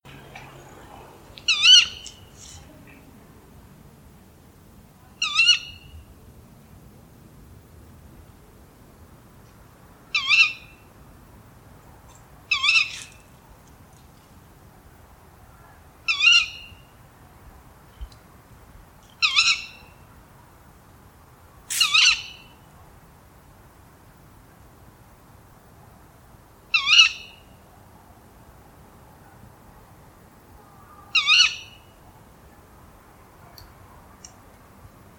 bosuil
🔭 Wetenschappelijk: Strix aluco
♫ contactroep vrouw filmpje 2016
bosuil_roep_vrouw.mp3